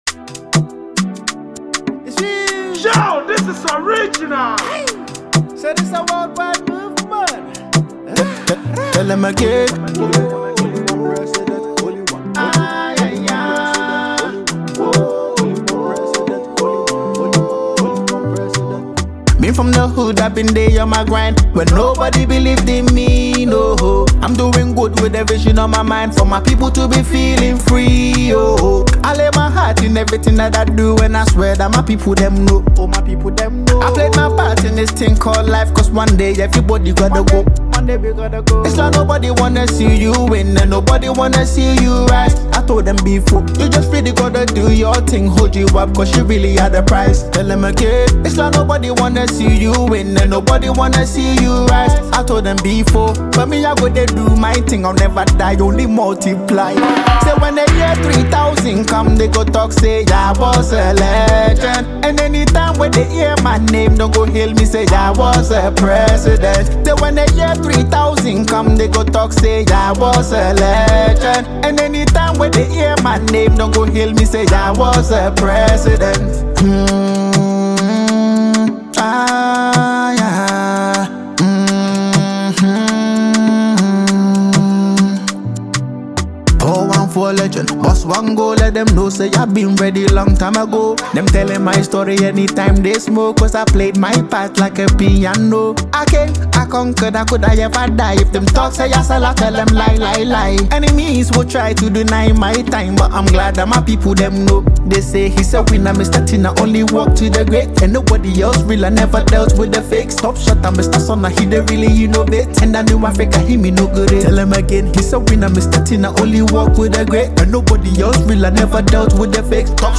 British-Ghanaian singer